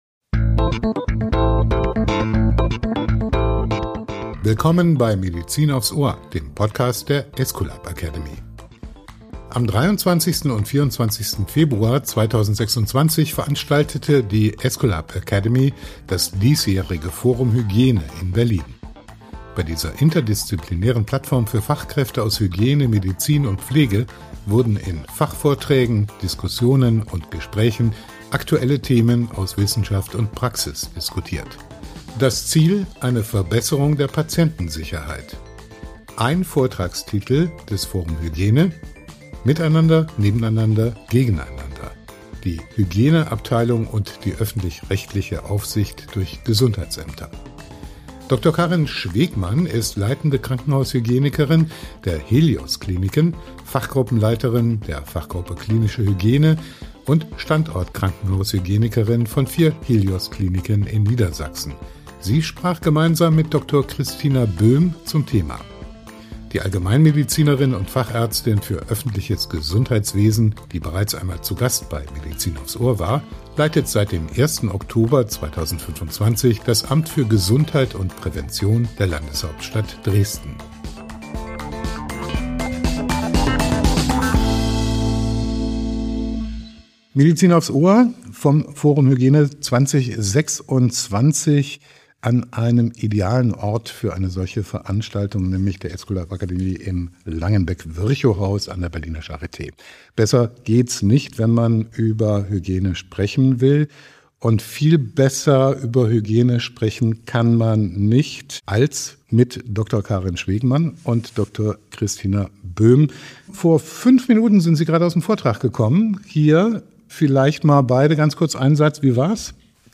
Nach ihrem Vortrag waren beide Medizinerinnen zu Gast bei Medizin aufs Ohr.